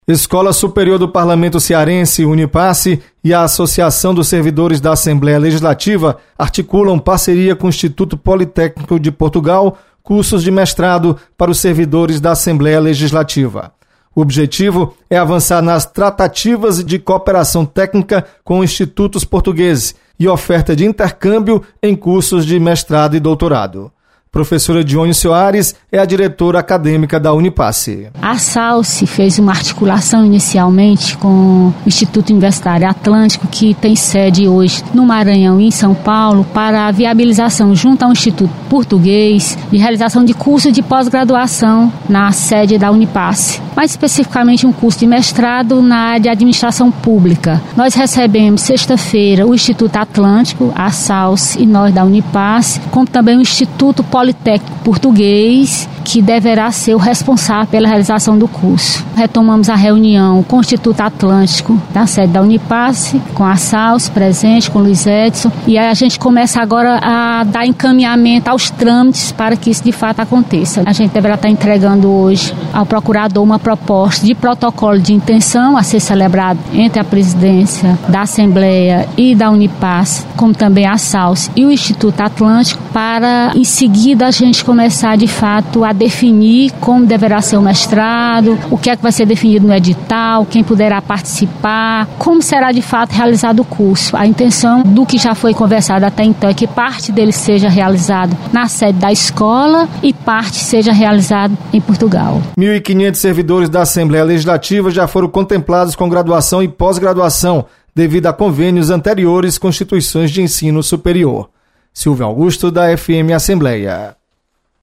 Unipace articula mestrado e doutorado em Portugal. Repórter